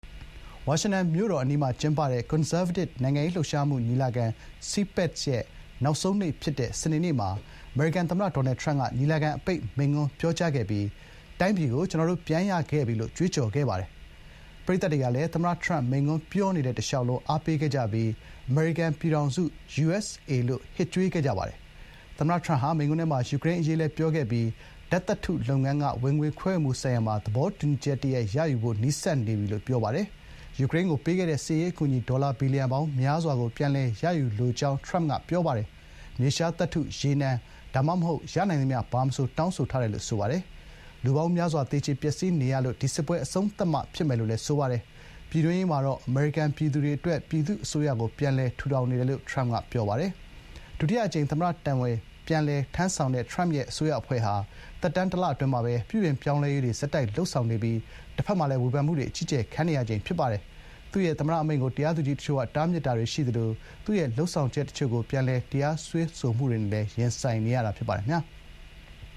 CPAC ကွန်ဆာဗေးတစ် ညီလာခံ သမ္မတထရမ့် မိန့်ခွန်းပြောကြား
ညီလာခံ တက်ရောက်တဲ့ ပရိသတ်တွေကလည်း သမ္မတ ထရမ့် မိန့်ခွန့်ပြောနေစဥ် တလျှောက်လုံး ထောက်ခံ အားပေးခဲ့ပြီး “အမေရိကန် ပြည်ထောင်စု - USA” ဆိုပြီး ဟစ်ကြွေးခဲ့ကြပါတယ်။